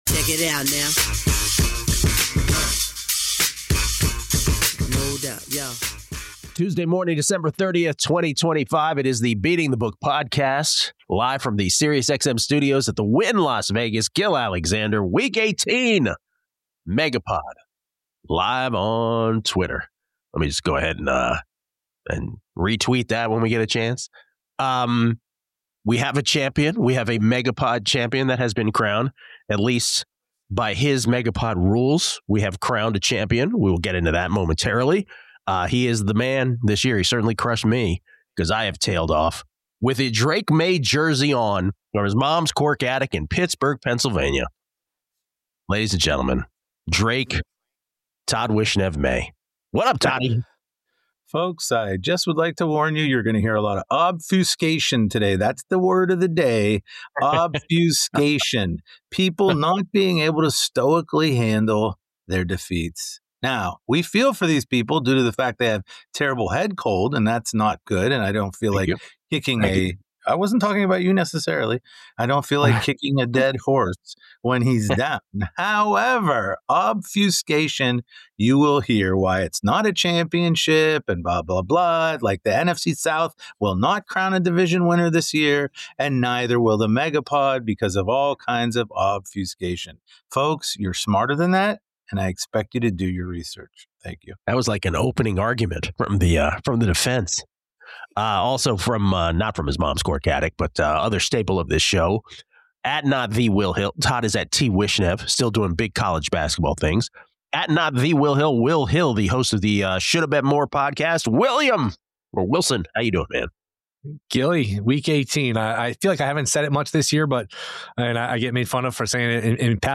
The quartet gives you their best bets of the week, best teaser options, which big favorite is most likely to lose outright, and the game they want no part of.